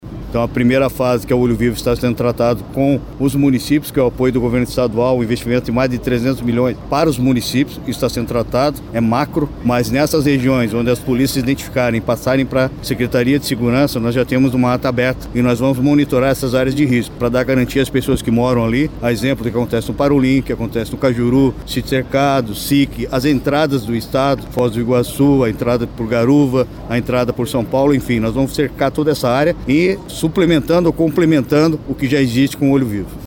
Hudson Teixeira disse que, em uma segunda fase, serão instaladas câmeras de monitoramento em regiões mais propensas às atividades ilícitas, como tráfico de drogas.